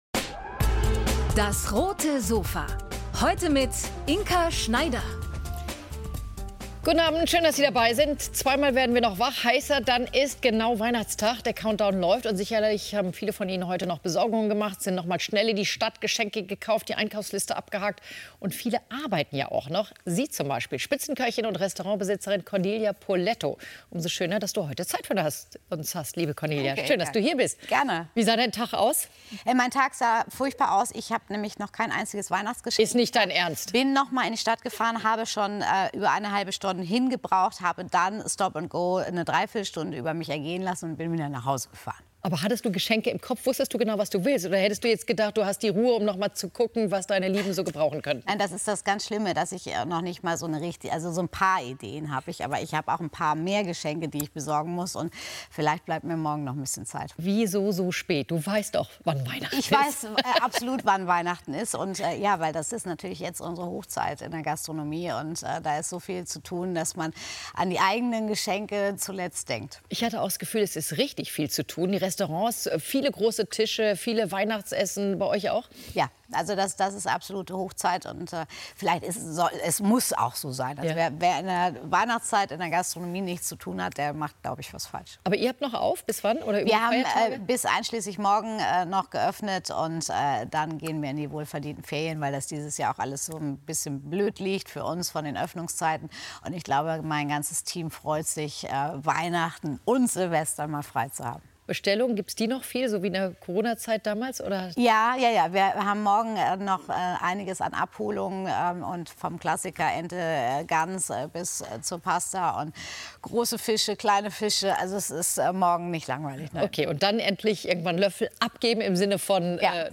Die Köchin spricht über die Balance zwischen Familie und Gastronomie und kocht mit Inka Schneider ein spezielles Weihnachtsessen.